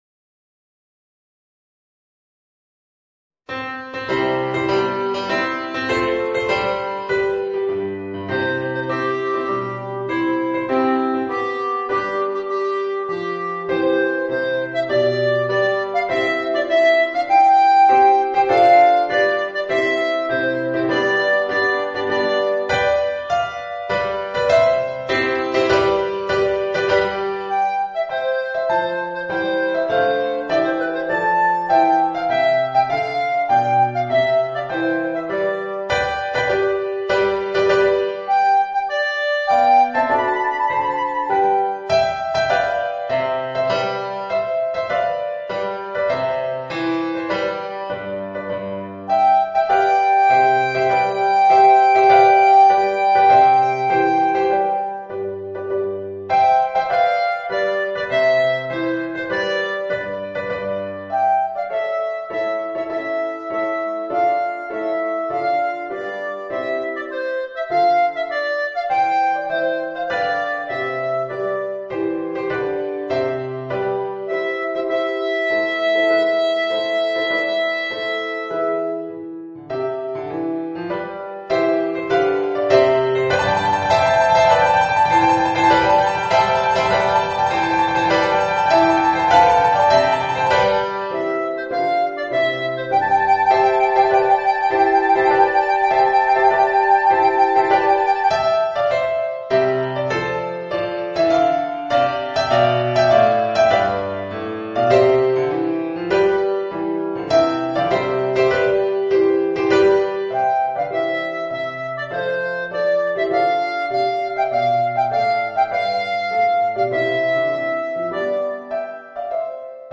Voicing: Clarinet and Organ